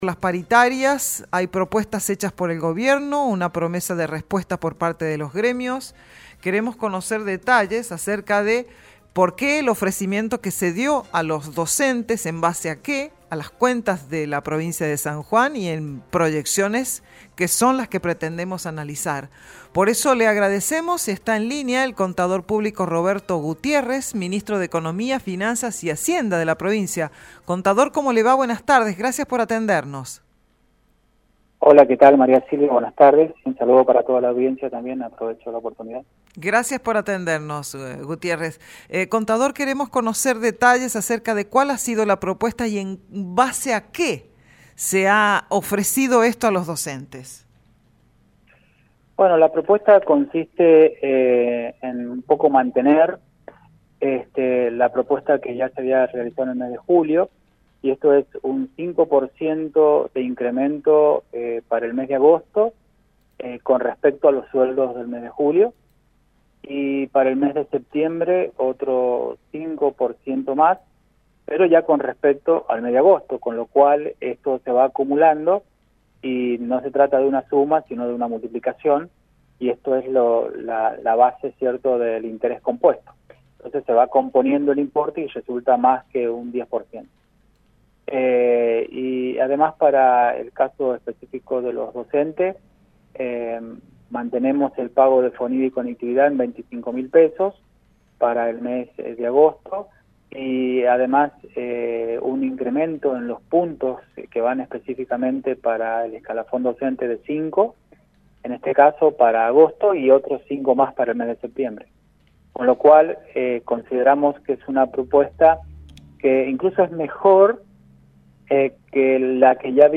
Así lo señaló en dialogo con ESTACION CLARIDAD el Ministro de Economía, Finanzas y Hacienda Roberto Gutiérrez.